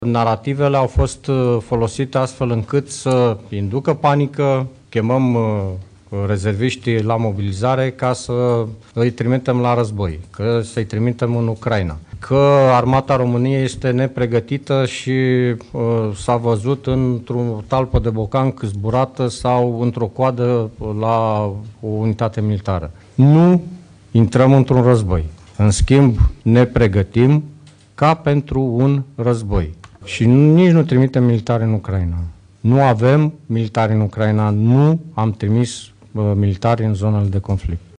Declarația a fost făcută într-o conferință de presă în care au fost prezentate rezultatele exercițiului de mobilizare a rezerviștilor, MOBEX.